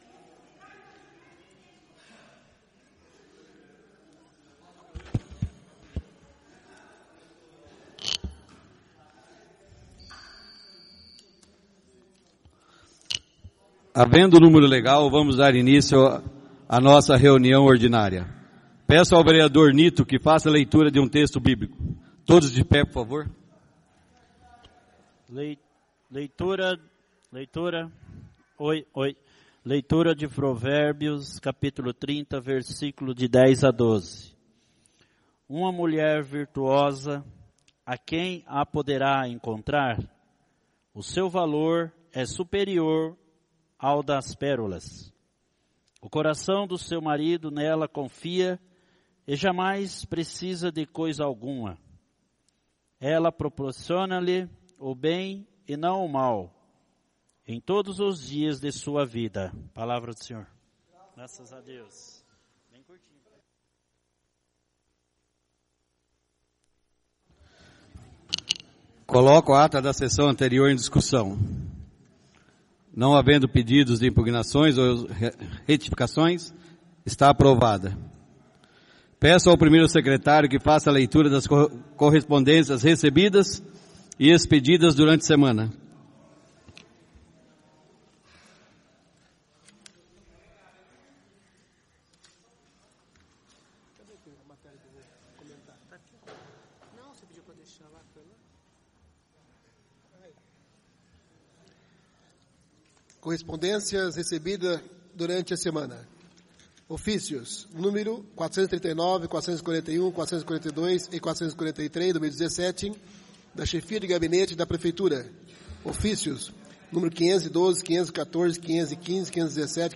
O Presidente da Câmara Municipal de Sarandi-Pr Sr. Carlos Roberto Falaschi, verificando a existência de quórum legal dá início à 19ª Reunião Ordinária do dia 12/06/2017.
A convite do Senhor Presidente, o edil José Aparecido da Silva procedeu à leitura de um texto bíblico.